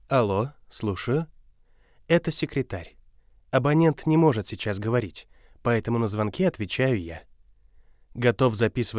На этой странице собраны дикторские голоса, которые мы можем записать для вашего проекта.
Профессиональный диктор с 10+ летним опытом